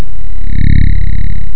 pulsar sound